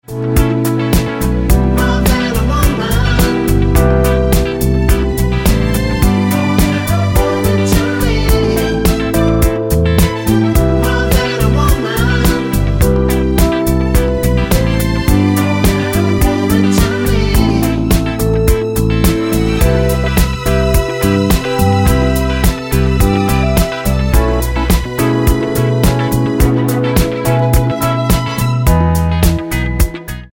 --> MP3 Demo abspielen...
Tonart:E mit Chor